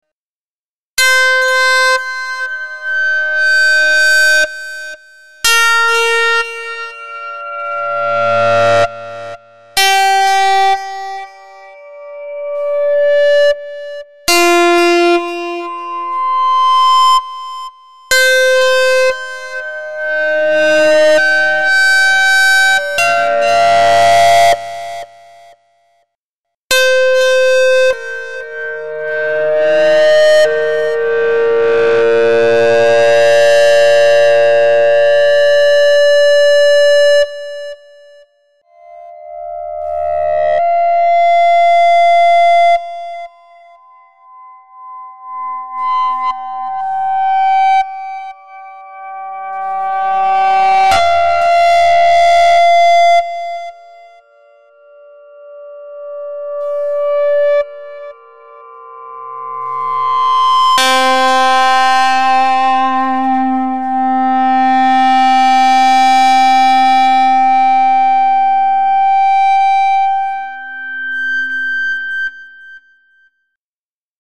For all examples, the asymmetric distortion model was used, as it produced the best distorted tone.
More Polyphonic Feedback
A short improvisation demonstrating the expressive capabilities of the polyphonic feedback control model